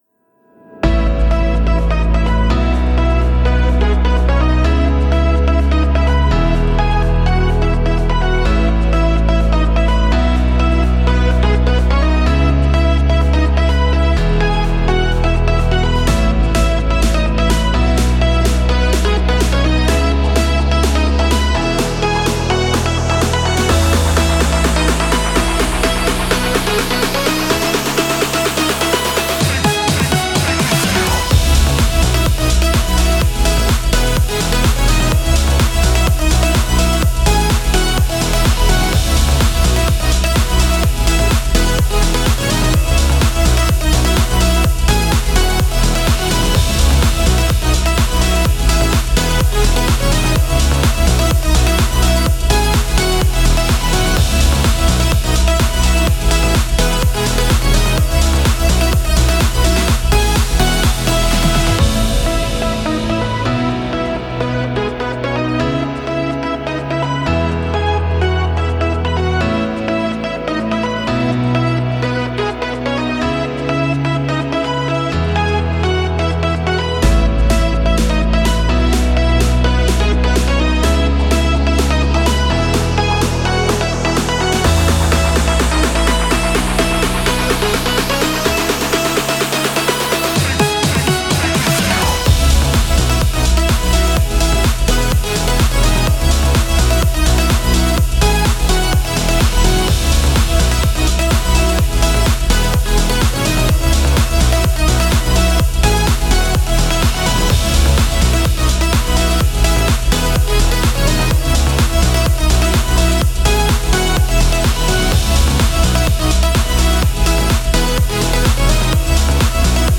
かっこいい/明るい/EDM
明るくかっこいい印象のシンプルなEDMです。